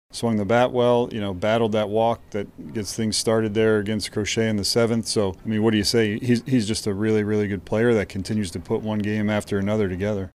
Royals manager Matt Quatraro says Maikel Garcia is impressive.
8-6-Royals-manager-Matt-Quatraro-says-Maikel-Garcia-is-impressive.mp3